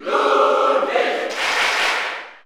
Category: Bowser Jr. (SSBU) Category: Crowd cheers (SSBU) You cannot overwrite this file.
Ludwig_Cheer_German_SSBU.ogg